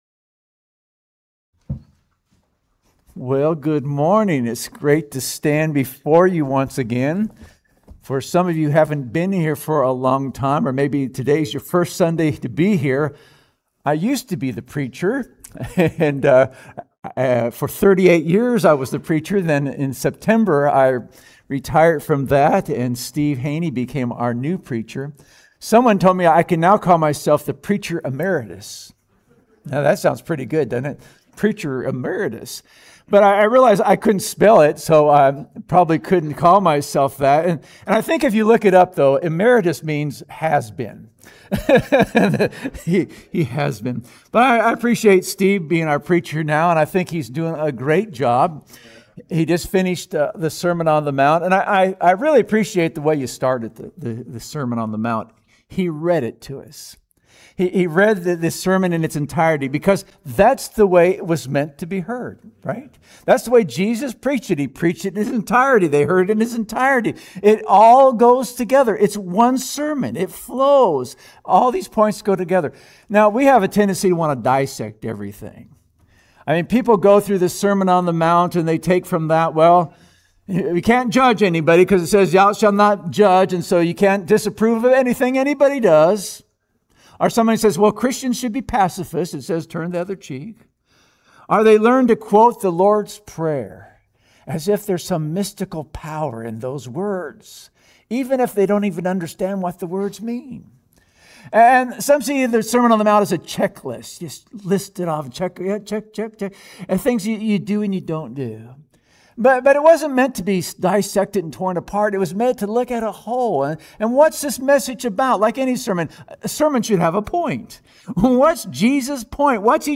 Sermons | Oregon City Church of Christ